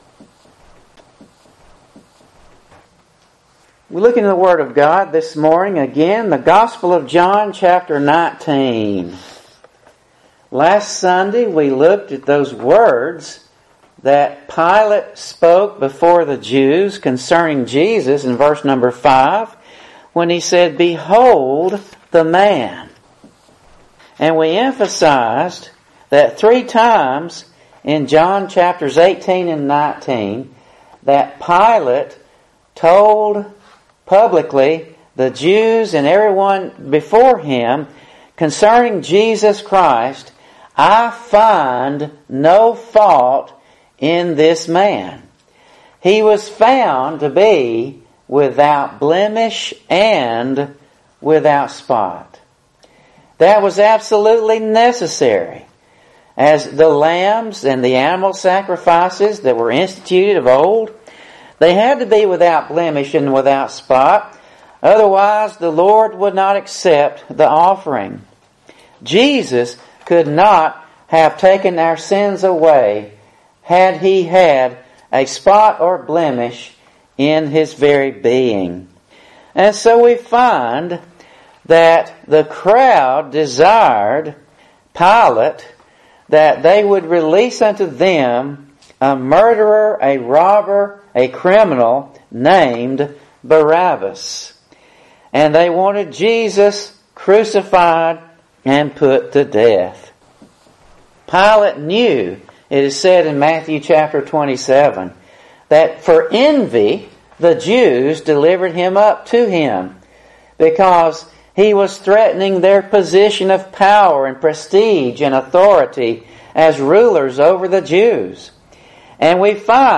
John 4:20-24, True Worship, In Spirit And Truth Jan 15 In: Sermon by Speaker